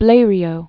(blārē-ō, blā-ryō), Louis 1872-1936.